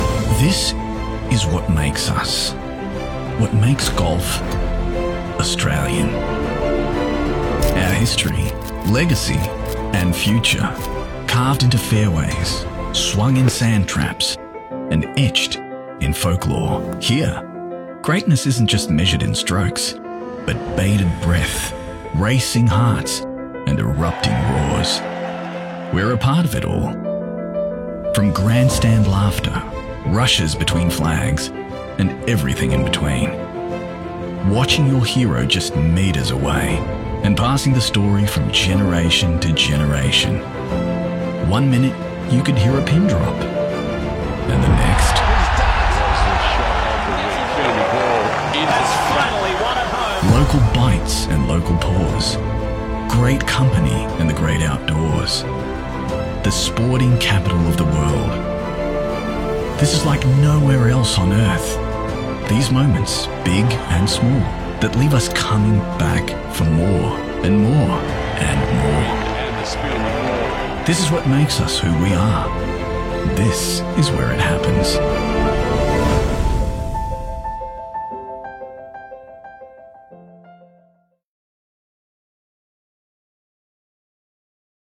Male
Television Spots
Words that describe my voice are Deep, Tenor, Credible.